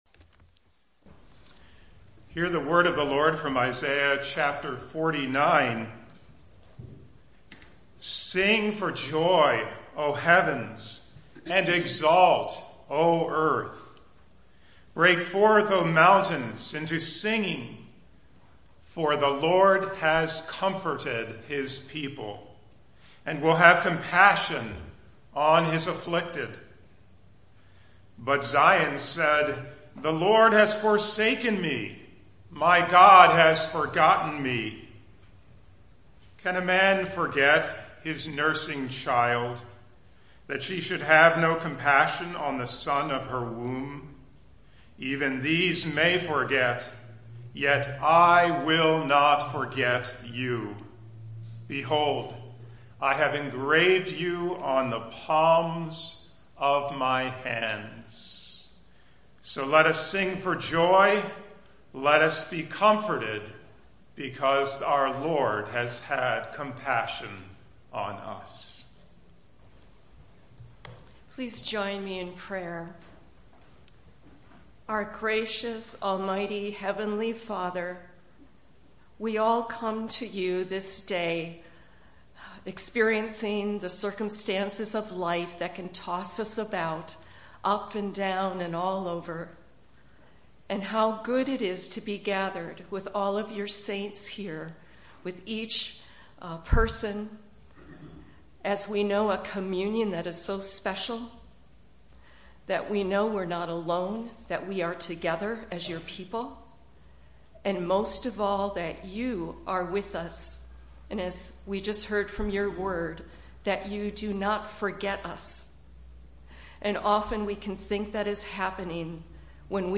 Thanks for joining us for our weekly worship! The services we post here were preached the previous week.